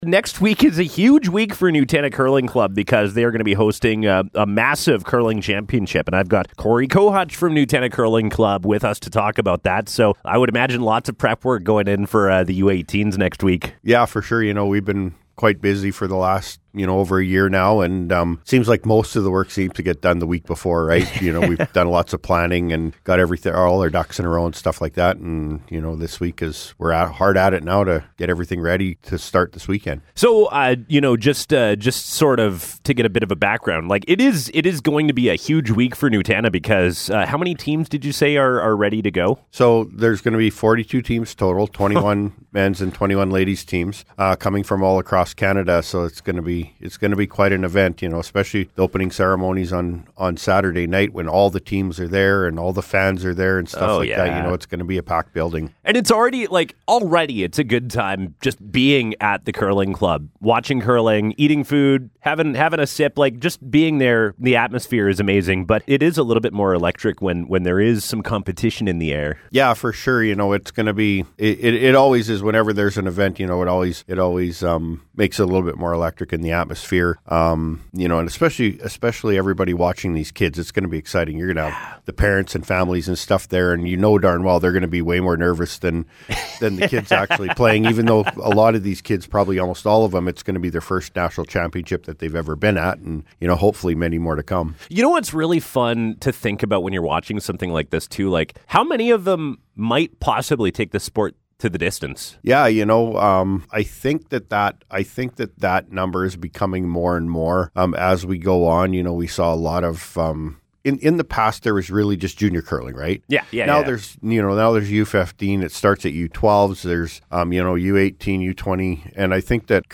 Interview: Nutana Curling Club